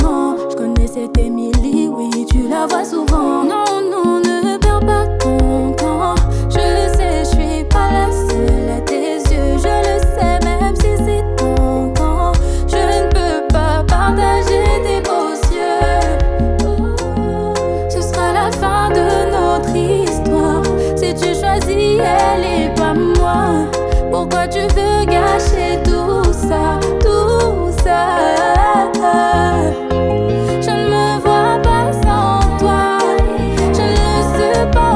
Жанр: Поп / R&b / Соул / Африканская музыка